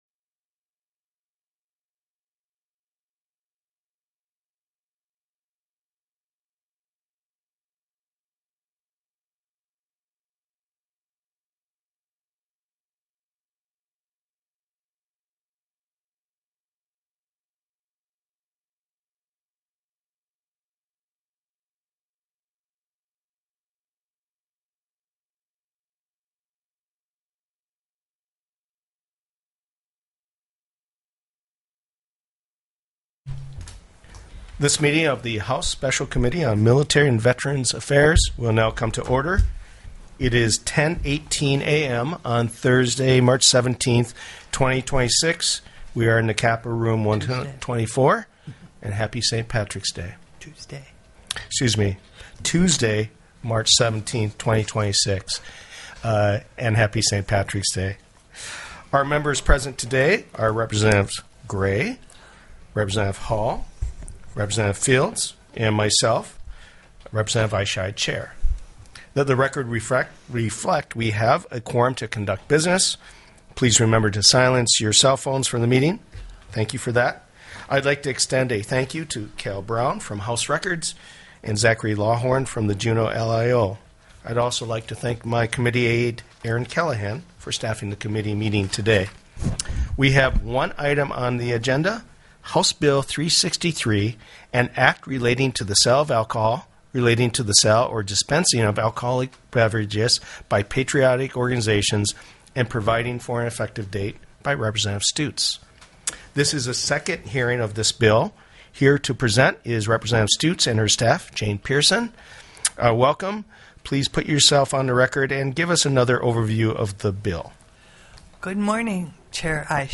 03/17/2026 10:15 AM House MILITARY & VETERANS' AFFAIRS
The audio recordings are captured by our records offices as the official record of the meeting and will have more accurate timestamps.
+= HB 363 ALCOHOL SALES BY PATRIOTIC ORGANIZATIONS TELECONFERENCED
-- Public Testimony --